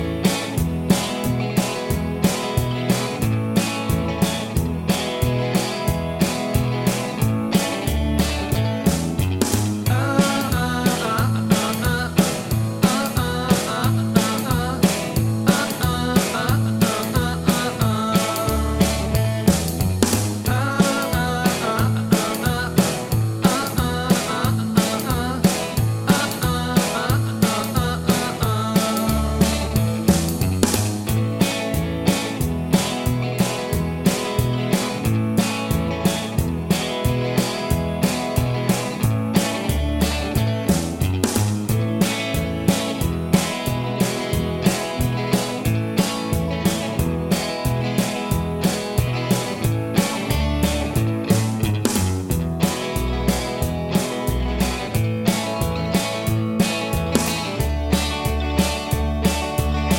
Rock 'n' Roll